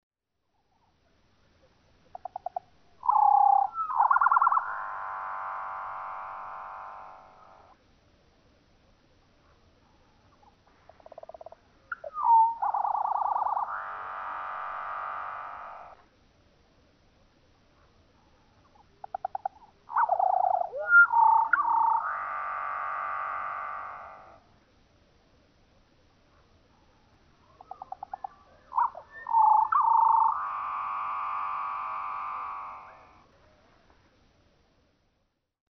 Seaside sparrow
The songs are an understated wheeze, but far more complex than our ears can readily detect.
Parker River National Wildlife Refuge, Newburyport, Massachusetts.
This seaside sparrow routinely sings four different songs, but our ears are challenged to appreciate the differences.
♫699. Here are those same four songs, but at one-quarter normal speed. More details can now be heard, but it takes a good auditory memory to remember the differences from one song to the next.
699_Seaside_Sparrow.mp3